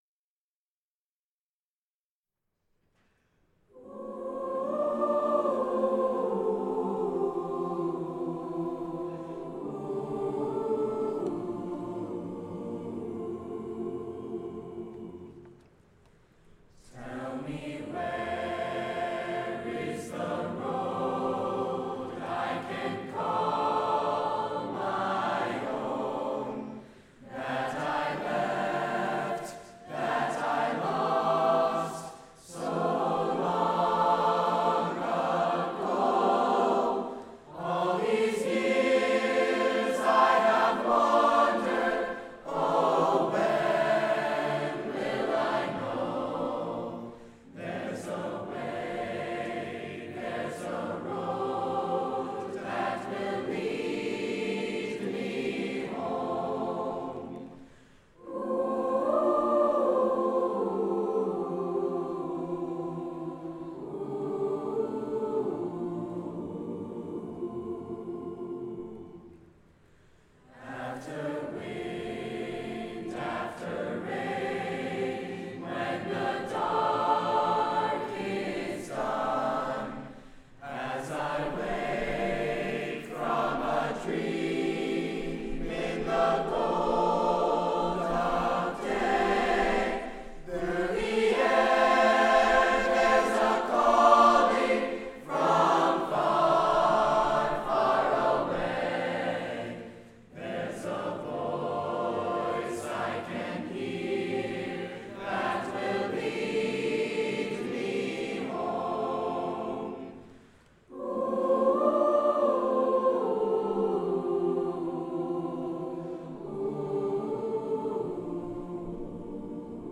Brookline High School Spring Music Festival
Combined Choirs